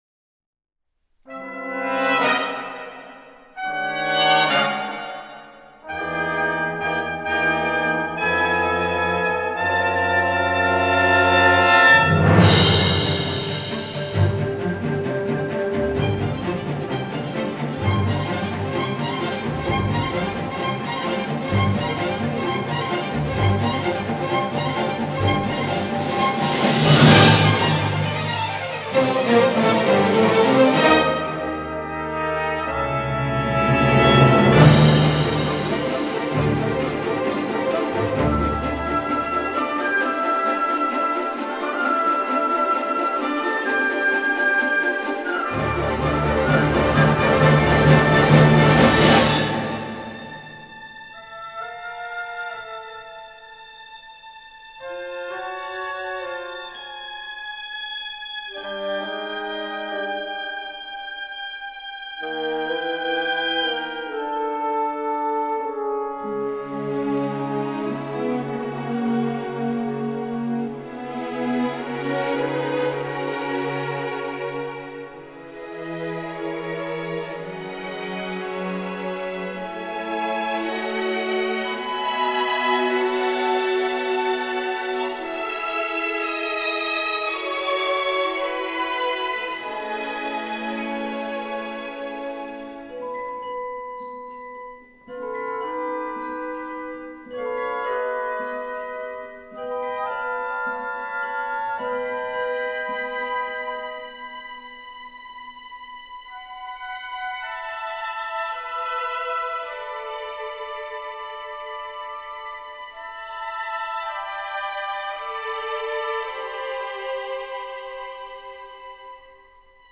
气势磅礴,扣人心弦的电影原声乐及主题曲